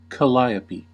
Ääntäminen
Synonyymit steam piano steam organ calliaphone Ääntäminen US Tuntematon aksentti: IPA : /kəˈlaɪə.pi/ Haettu sana löytyi näillä lähdekielillä: englanti Käännös Substantiivit 1. Dampforgel {die} Määritelmät Substantiivit A musical organ , consisting of steam whistles played with a keyboard .